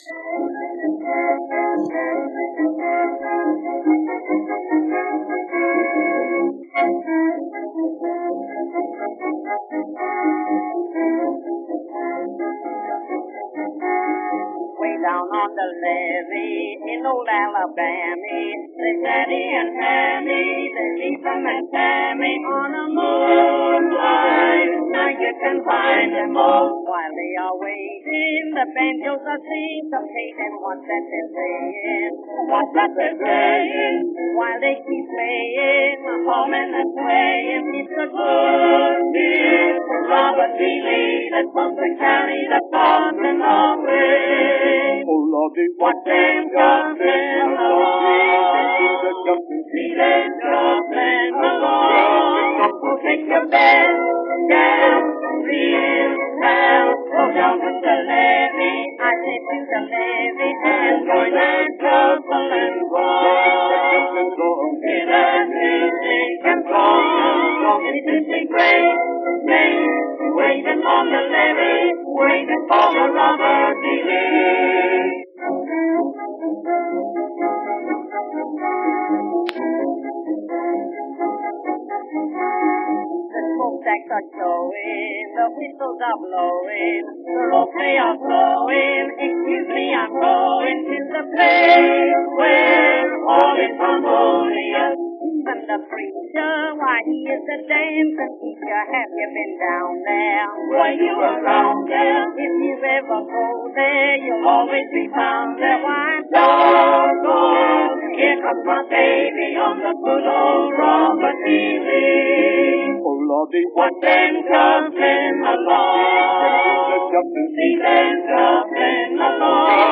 Two classic ragtime tunes originally recorded in 1912.